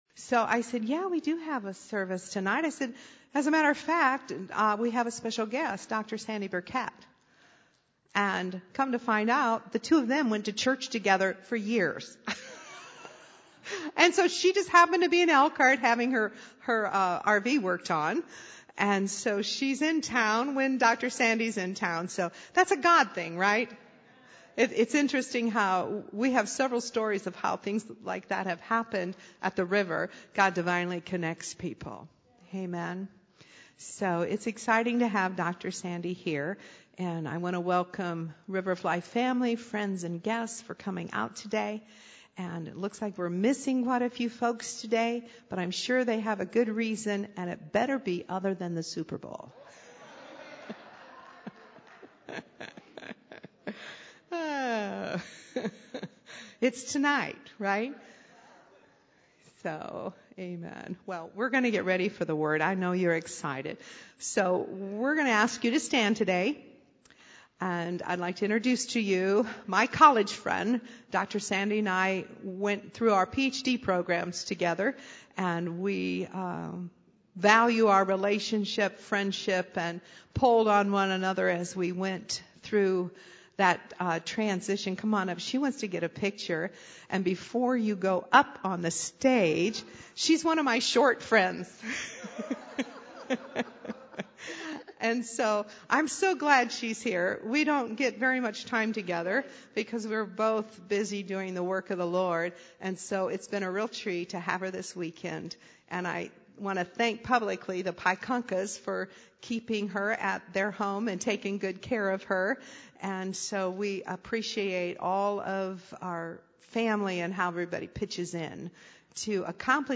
Sermons Archive - Page 48 of 52 - Praysers Ministries, Inc.